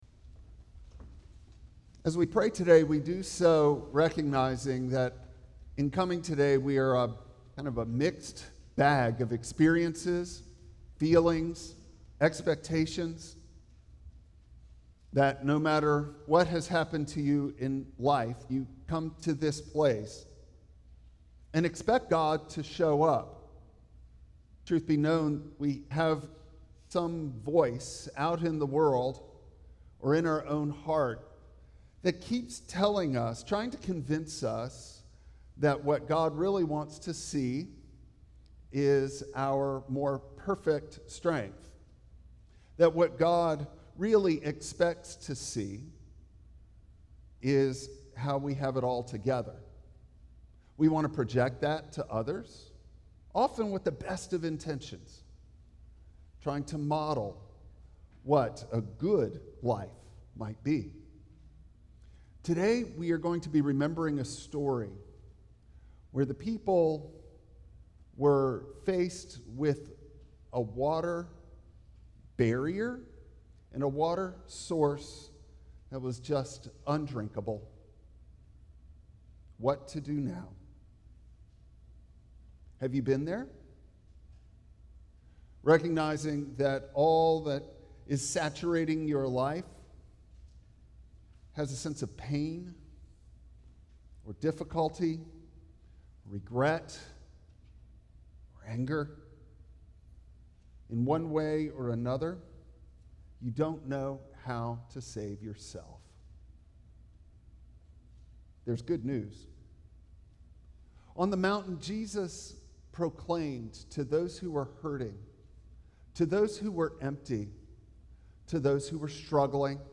Exodus 15:22-27 Service Type: Traditional Service Bible Text